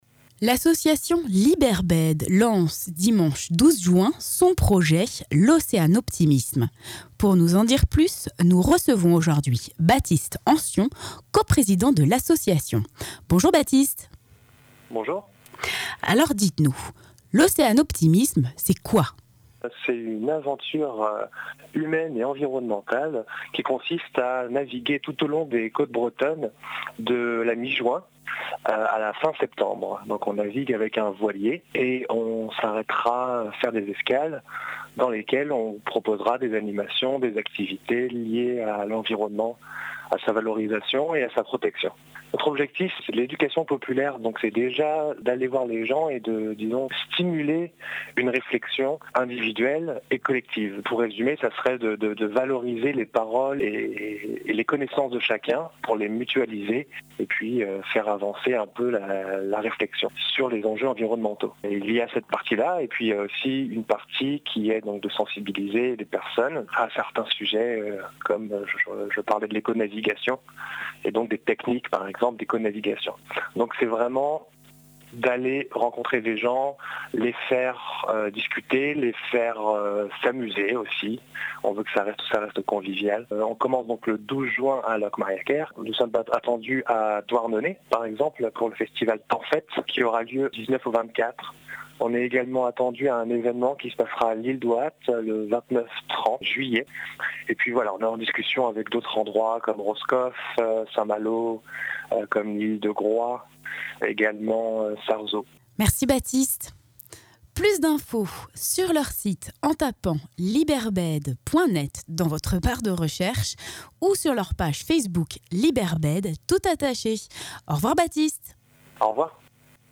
Interview de RMN-FM dans l’émission « Le coup de fil du jour » du mardi 7 juin 2016